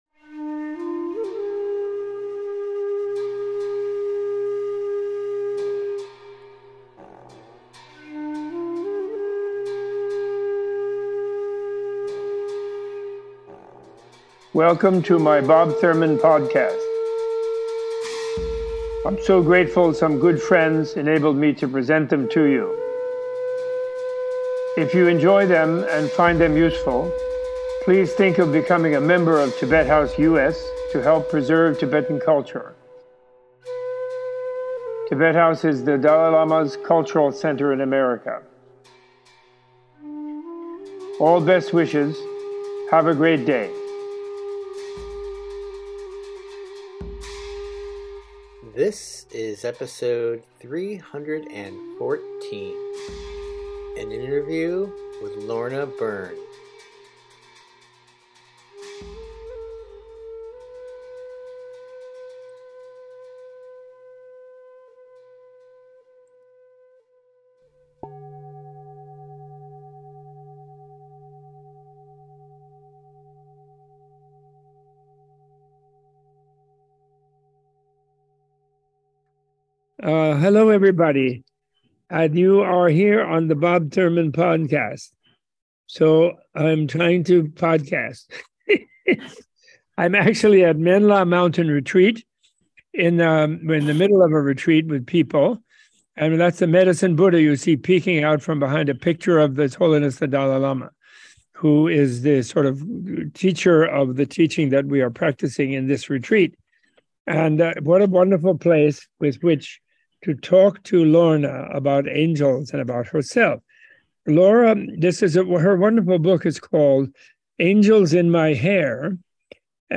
Opening with a recommendation of “Angels in My Hair” by Lorna Byrne, Robert Thurman sits down with its author for a far-ranging discussion of faith, angels, materialism, climate change and the power of positive thinking and prayer. This podcast includes: a discussion of the commonalities of all faiths and religions, an in-depth exploration of the divine beings and protectors found in Christian and Buddhist traditions, and a heart-opening dialogue on love, the soul, the afterlife, and the non-dual nature of reality. The episode concludes with an extended discussion of guardian angels and a guided meditation led by Lorna.